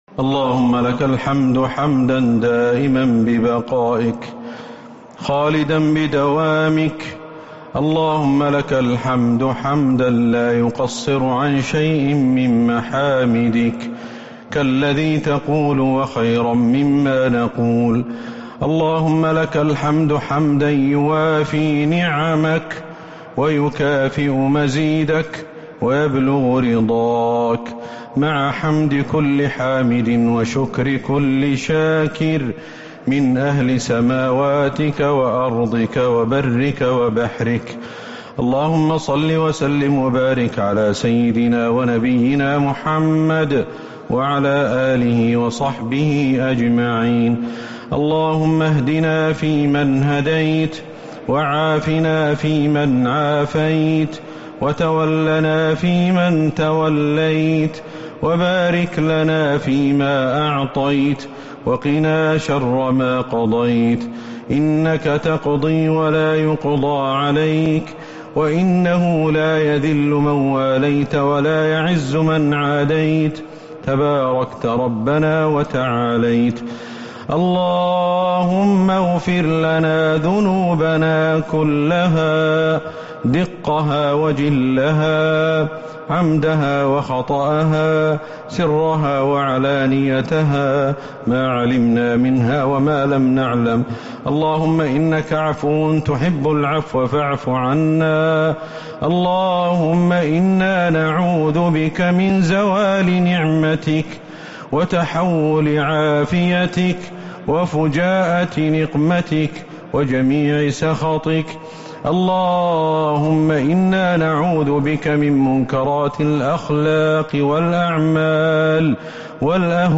دعاء القنوت ليلة 15 رمضان 1443هـ | Dua for the night of 15 Ramadan 1443H > تراويح الحرم النبوي عام 1443 🕌 > التراويح - تلاوات الحرمين